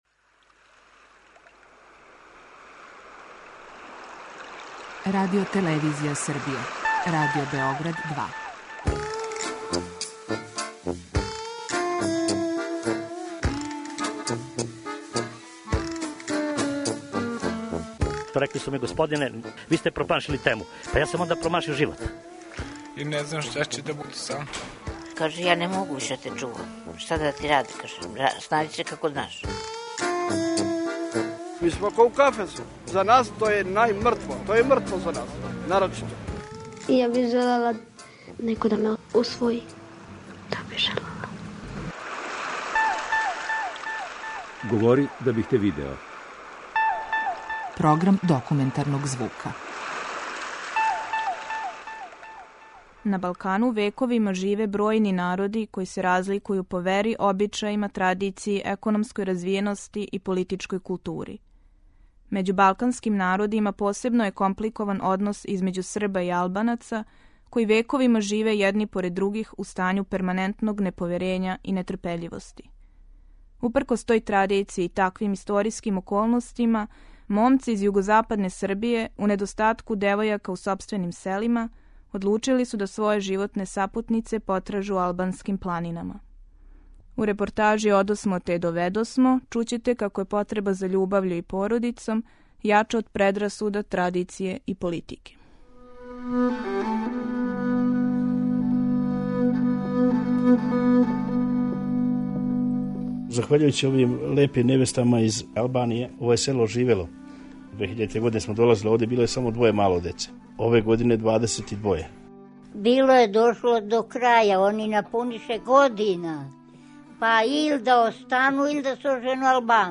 Документарни програм: Одосмо те је доведосмо
Репортажа Одосмо те је доведосмо прича је о томе како је потреба за љубављу и породицом јача од предрасуда, традиције и политике. Конципирана као полифонија гласова различитих сведока, ова репортажа из различитих перспектива проблематизује и покрива тему мешовитих бракова између српских младожења и албанских невести, у исто време покрећући и друге значајне теме данашњице, као што је питање статуса породице као институције у савременом свету или последице миграција из села у град.